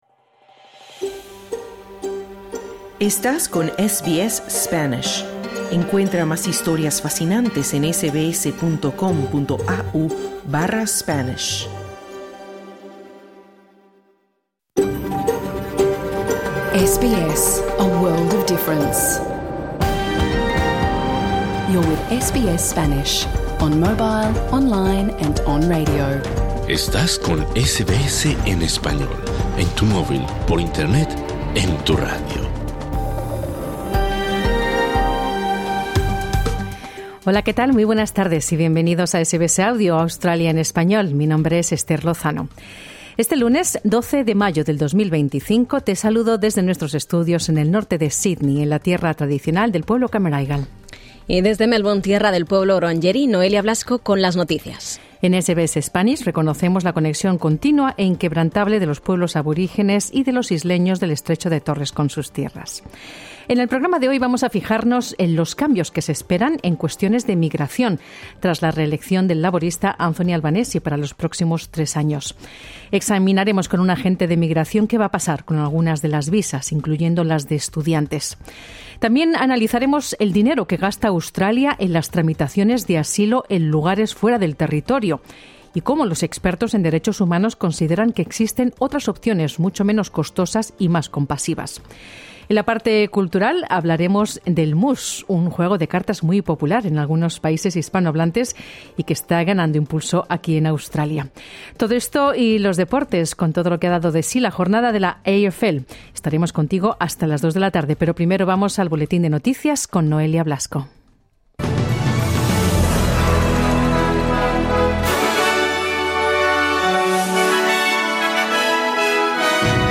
Examinamos con un agente de migración qué va a pasar con algunas de las visas, incluyendo las de estudiante.